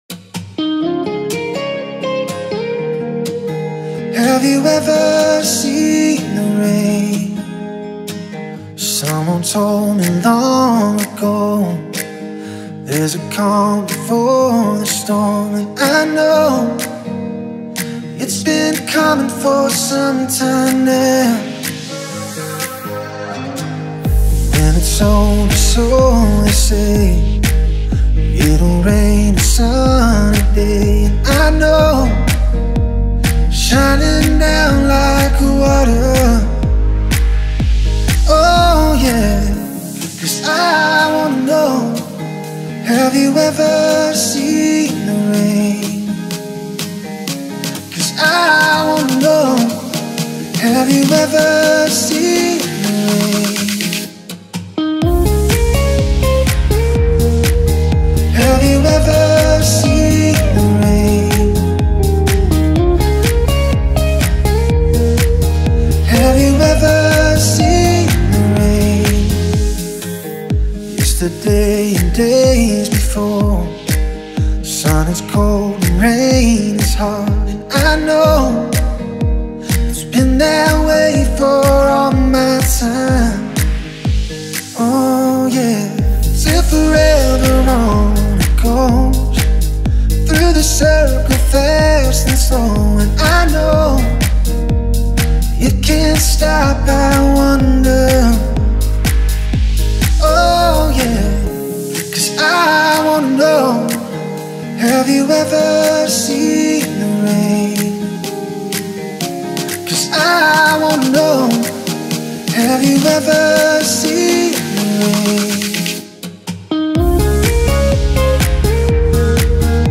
это трогательная и меланхоличная песня в жанре рок